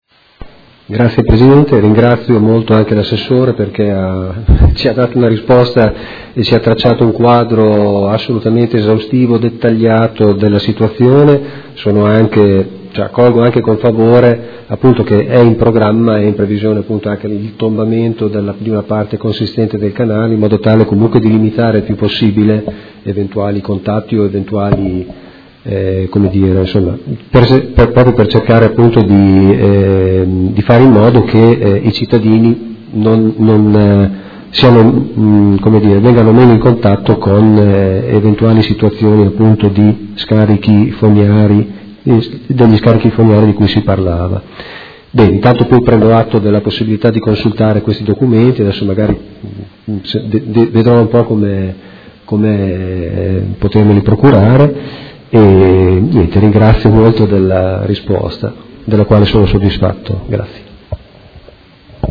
Seduta del 26/03/2018 Replica a risposta Assessore Guerzoni. Interrogazione dei Consiglieri Malferrari e Chincarini (Art.1-MDP/Per Me Modena) avente per oggetto: Acqua inquinata e maleodorante in un canale alla Madonnina